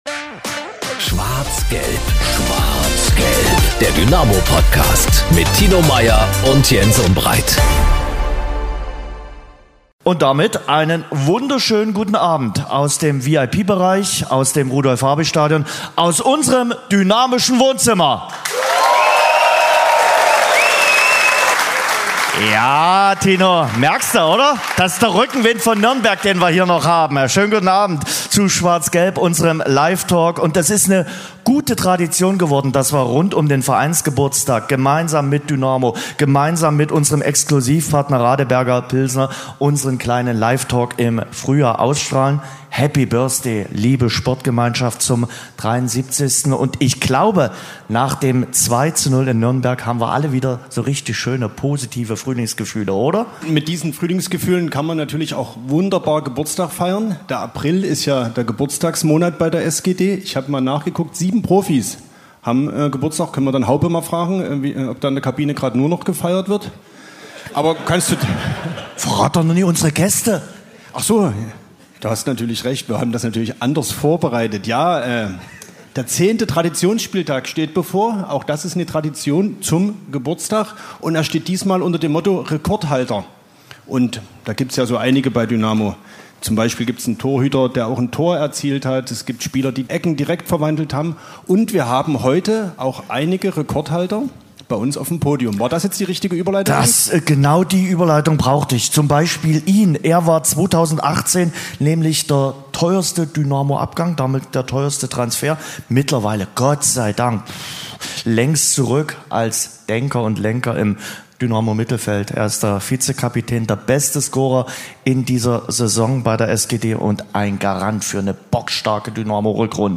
#168 Der Dynamo-Livetalk vor dem Zweitliga-Endspurt mit Hauptmann, Fiel & Gütschow ~ SCHWARZ GELB - Der Dynamo-Podcast Podcast